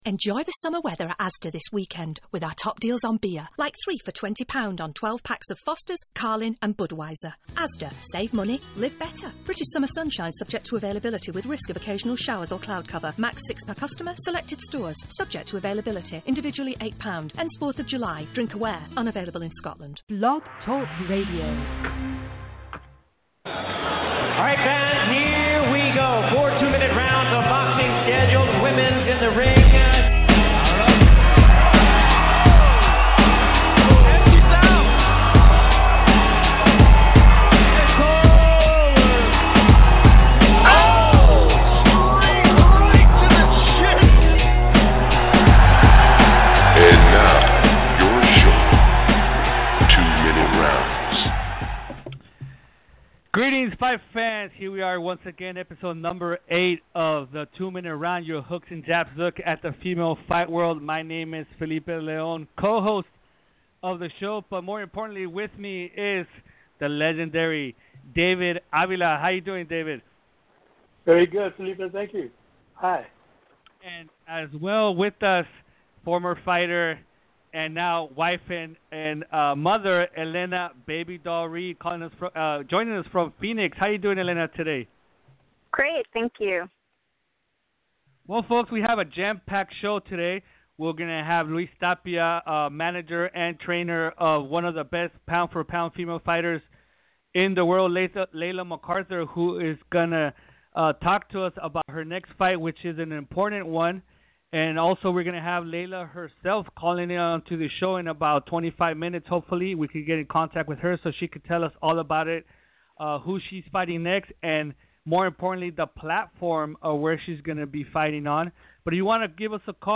A bi-weekly podcast focused on all the news, notes, results, upcoming fights and everything in between in the wide world of female professional boxing. This week, the 2 Minute crew talks fight results, the upcoming calendar, female fight chatter ..